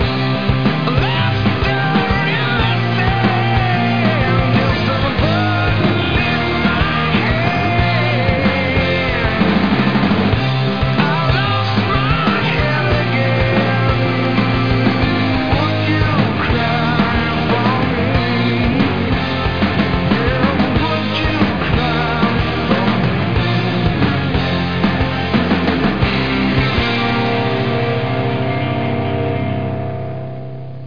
rock5-2.mp3